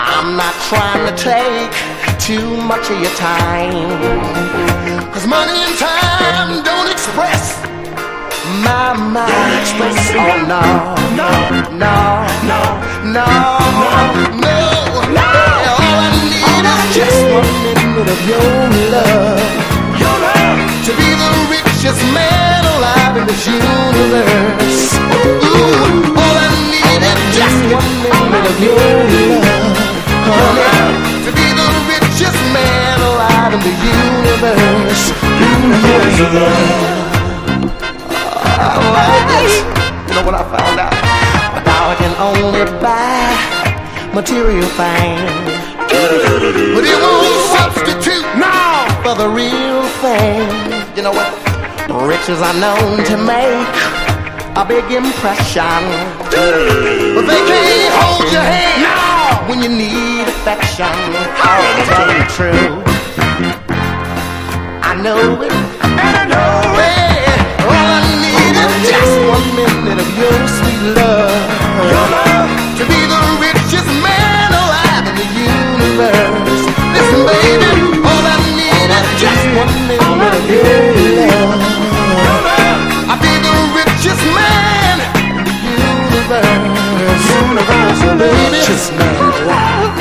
デトロイトのヴォーカルグループ